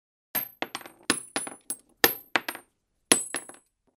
Звуки падения гильзы
Звук падения монтажных гильз